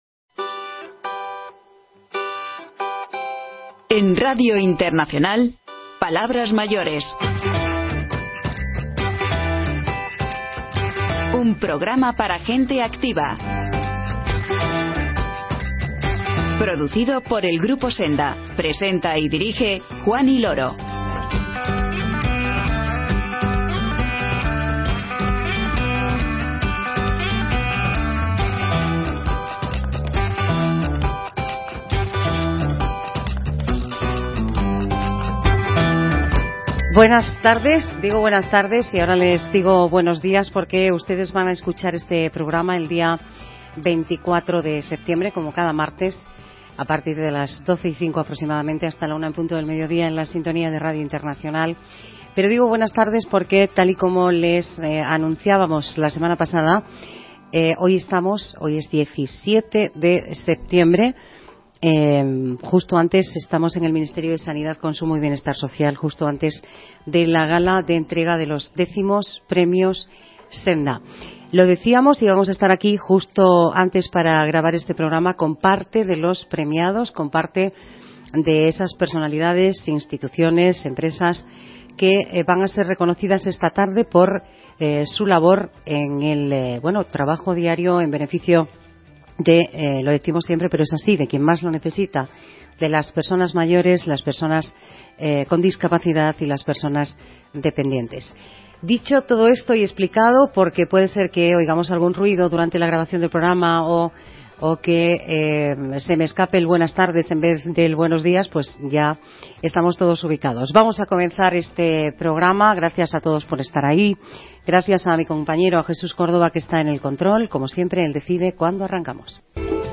En este programa especial realizado desde la gala de entrega de los X Premios Senda, conversamos con la directora General de Planificación, Ordenación y Adaptación al Cambio Social de la Consejería de Derechos Sociales y Bienestar del Principado de Asturias, Jimena Pascual, y con el Director General de Cuidados, Humanización y Atención Sociosanitaria, Sergio Valles, que recogieron el Premio Senda 2019 a la Iniciativa Pública.